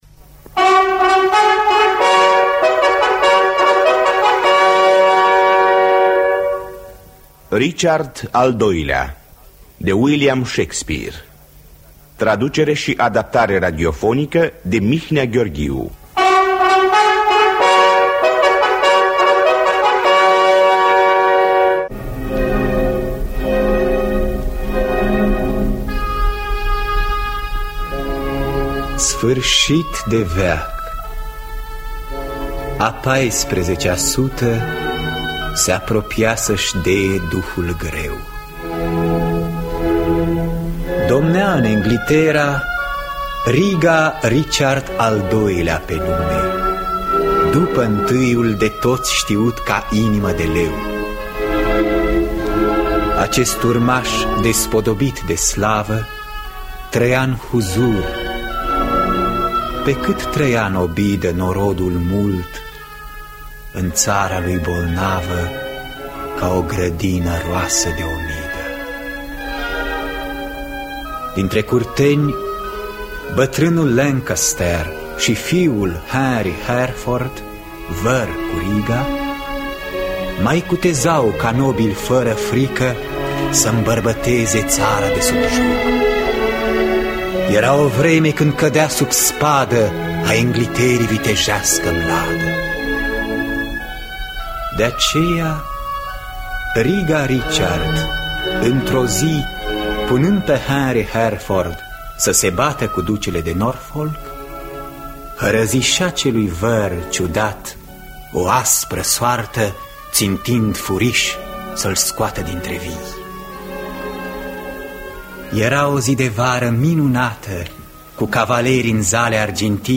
Traducerea și adaptarea radiofonică de Mihnea Gheorghiu.